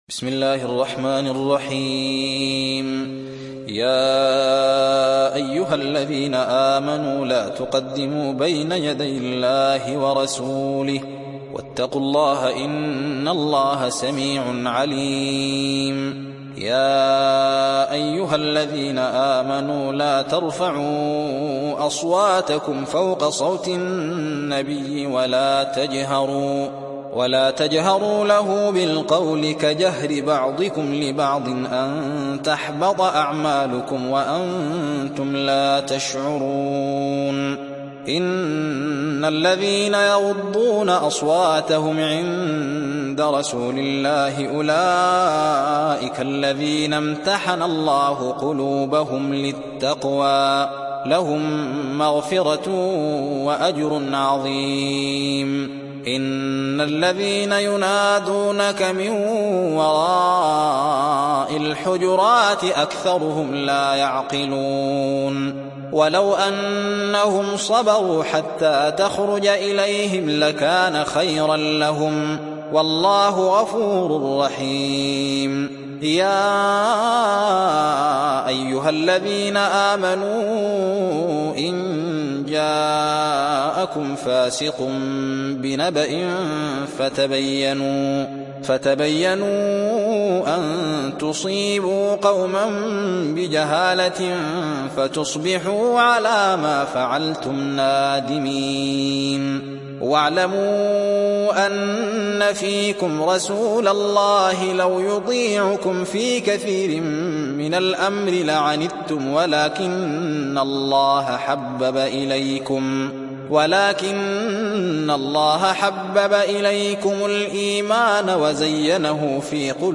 Hafs থেকে Asim